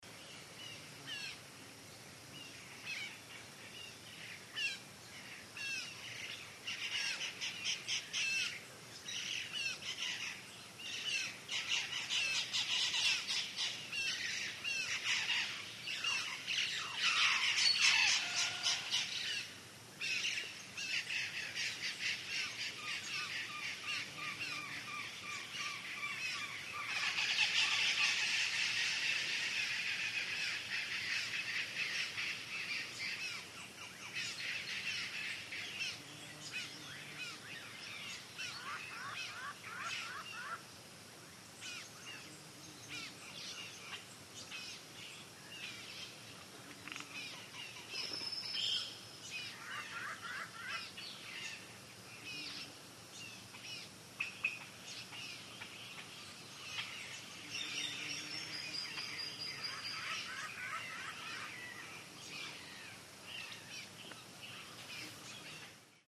Belize jungle with parrots, buzzing fly ( Cayo district )